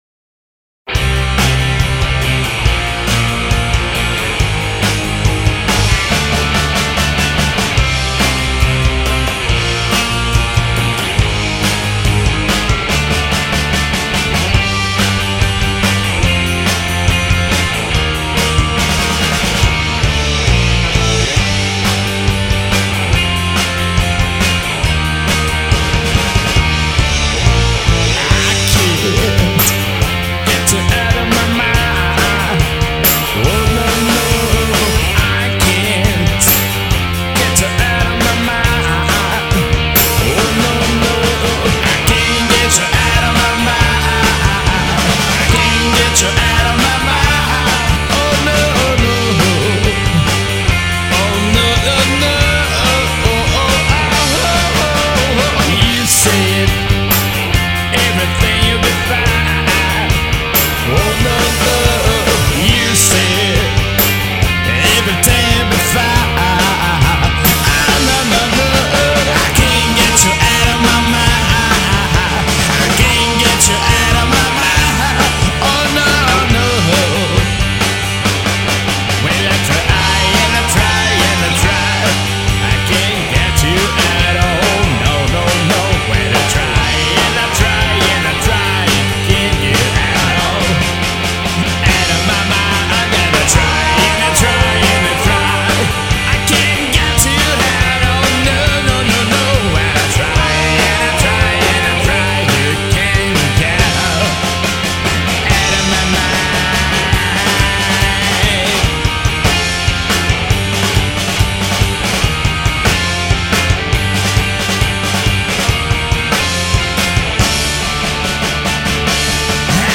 EstiloPunk Rock